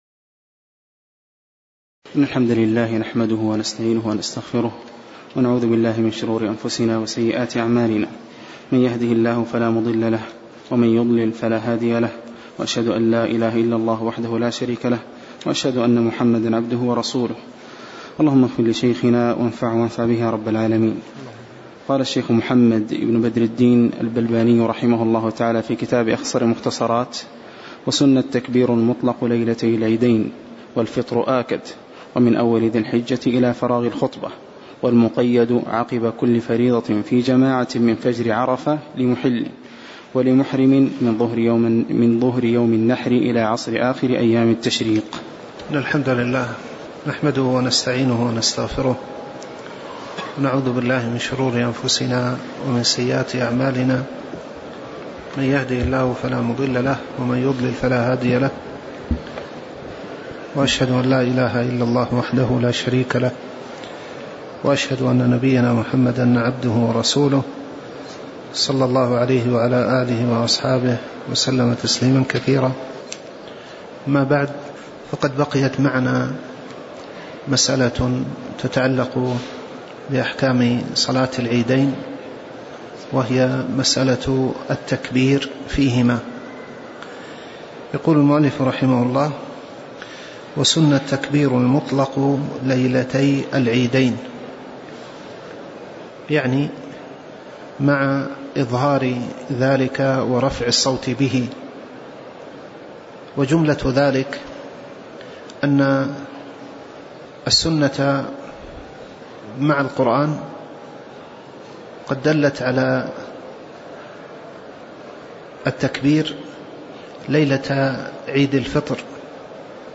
تاريخ النشر ٢٨ رجب ١٤٣٩ هـ المكان: المسجد النبوي الشيخ